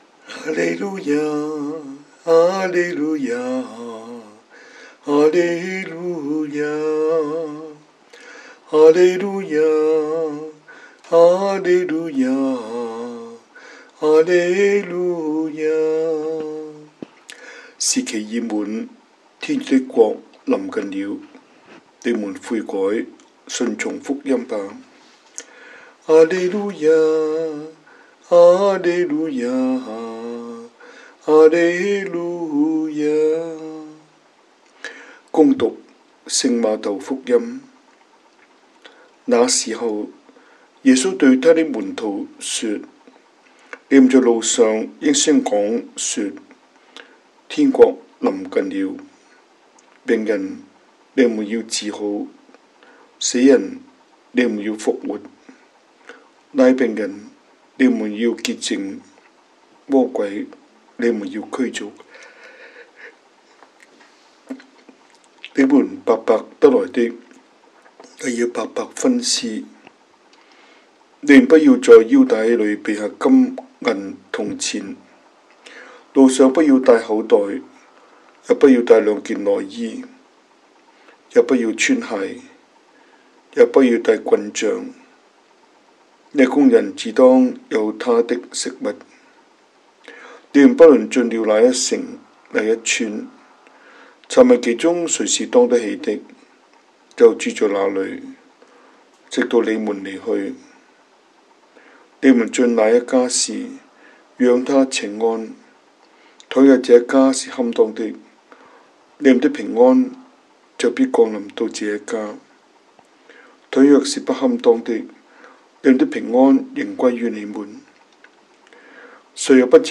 中文講道, 英文講道